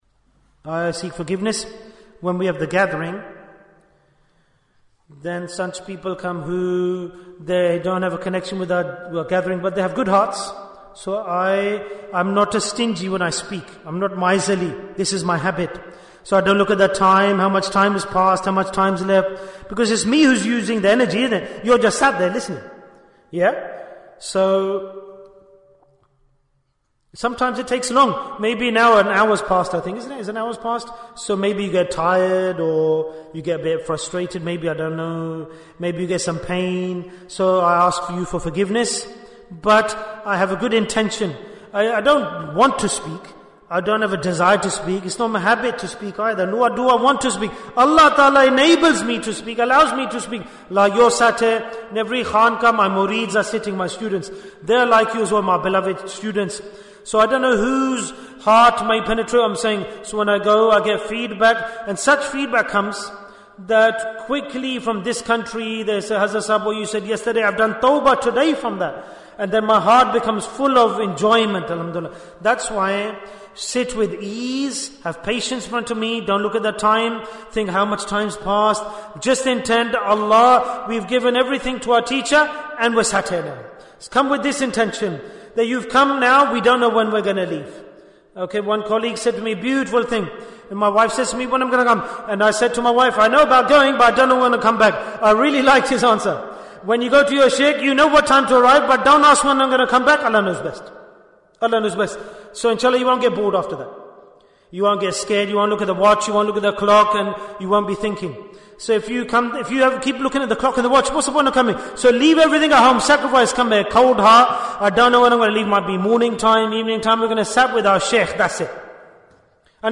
Talk before Dhikr 1121 minutes21st November, 2024